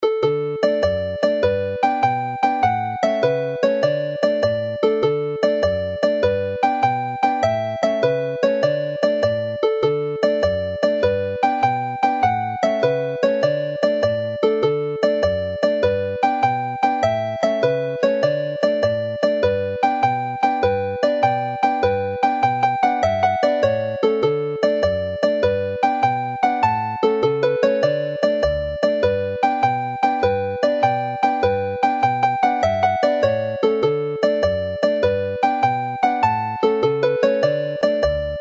closes the set in a more cheerful mood.